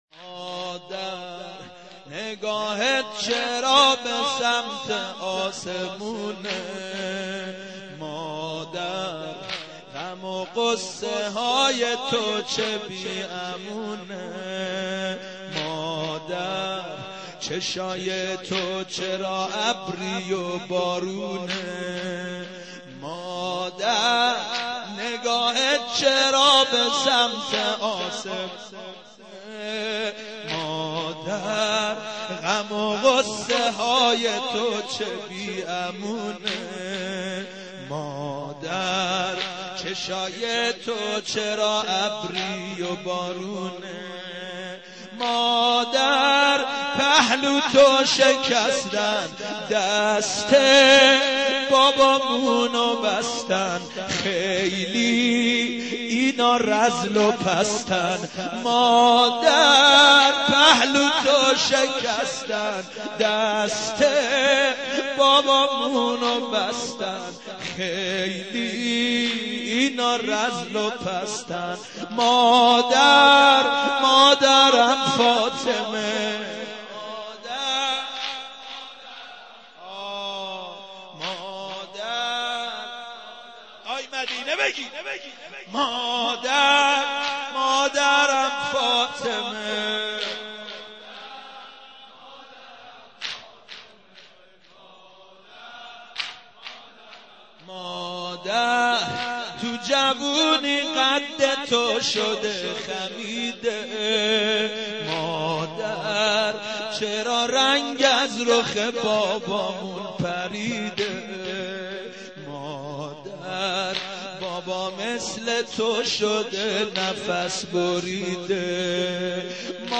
دانلود/ سه مداحی ویژه ایام فاطمیه